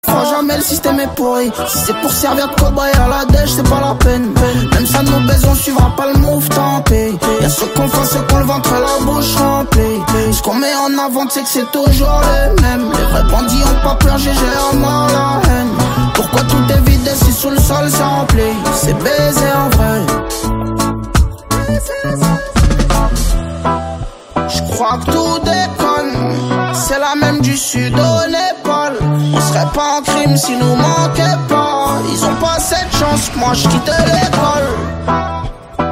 Catégorie Rap / Hip Hop